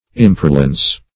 Imparlance \Im*par"lance\, n. [Cf. Emparlance, Parlance.]